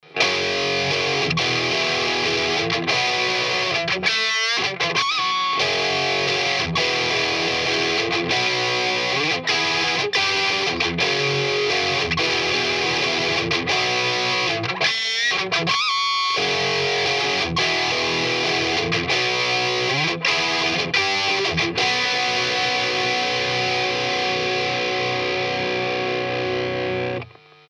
Цепь записи: Fender Telecaster California -> Chris Custom Combo -> Shure SM85 смотрящий в динамик   AKG C1000 в метре от него -> Рековая звуковая карта MOTU 828 MkII
Предисловие - семплы выдраны прямо из песни. Звук "без всего" суховат, но в микс все ложиться прекрастно  ;)
Цепь записи - все та же, что написана сверху, только теперь я грею грязный канал скримером :)
mp3) - Это просто рифф из припева :) Настройки как на 1 семпле, только гейну там на 13 часов.
VerseRiff.mp3